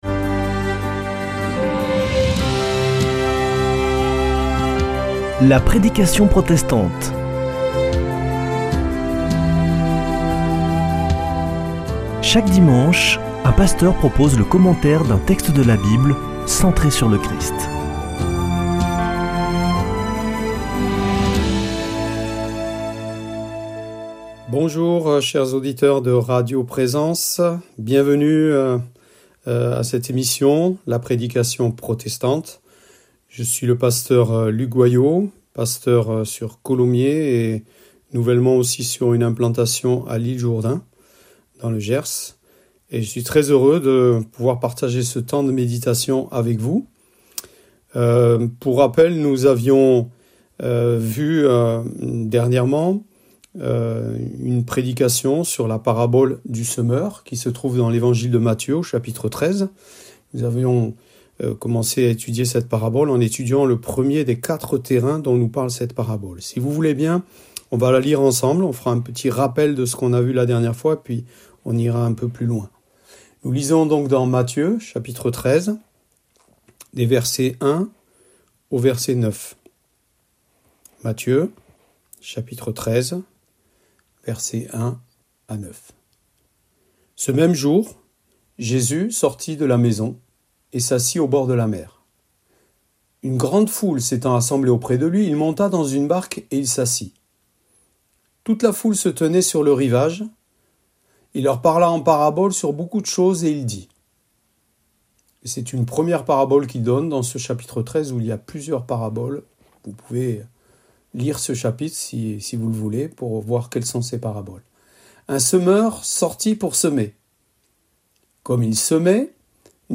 Accueil \ Emissions \ Foi \ Formation \ La prédication protestante \ La semence sur le terrain pierreux et sur le terrain épineux.